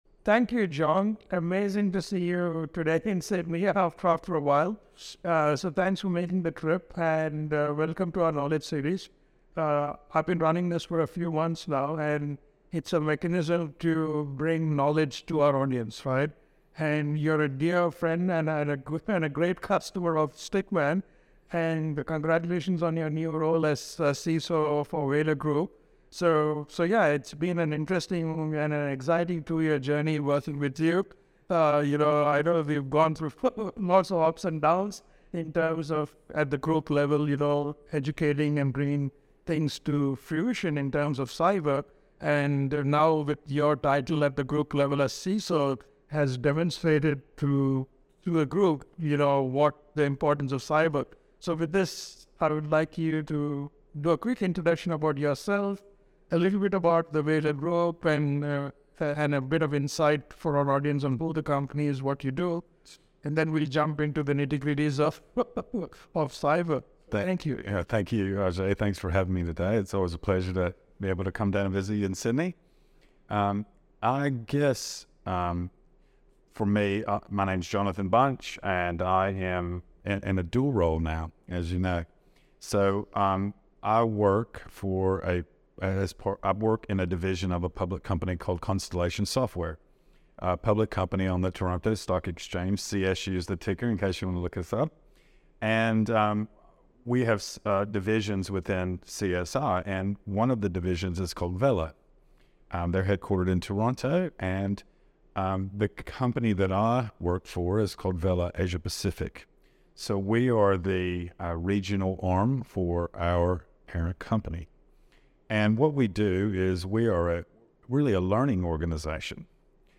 As part of our mission to educate, we are starting an Interview series that is focused on cybersecurity and its relationship with other industries.